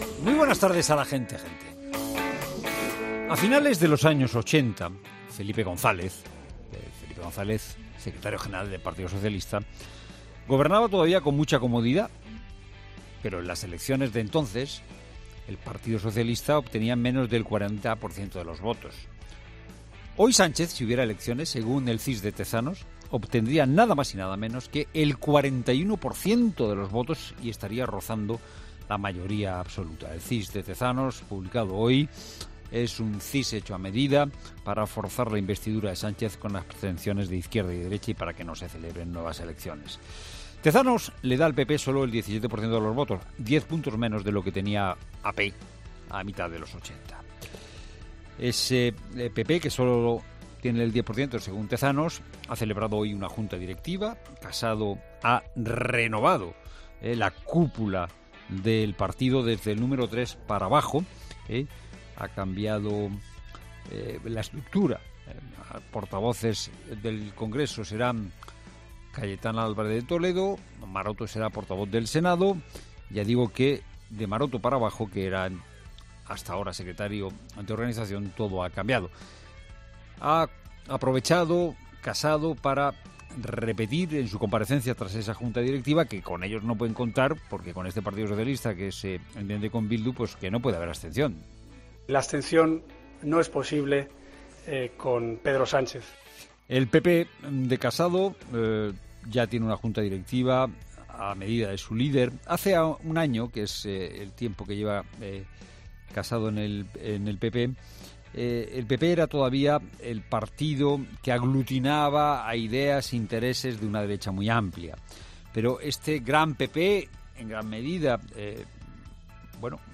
Monólogo de Fernando de Haro
El presentador de 'La Tarde' de COPE, Fernando de Haro ha comenzado su monólogo de este martes centrándose en la situación vivida por el Partido Popular desde la llegada de Pablo Casado a la cima del partido.